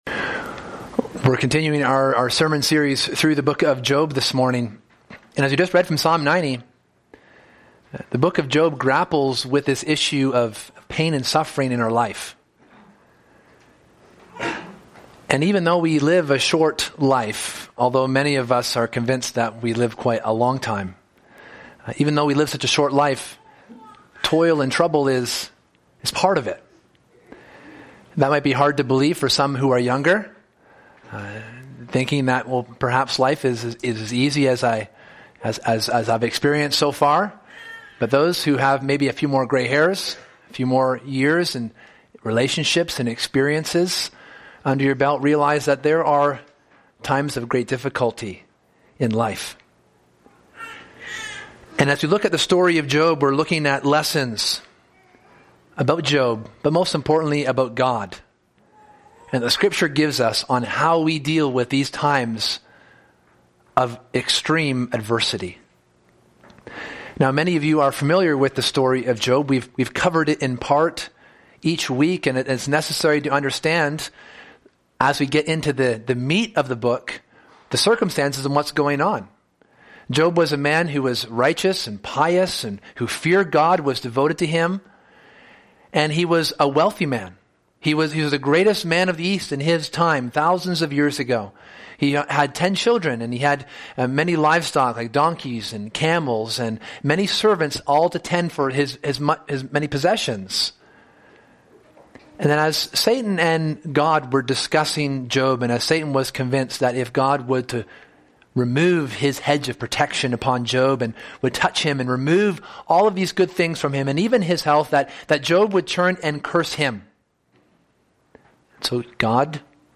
Sunday AM
This book, and thus this sermon series, explores the nature and character of God.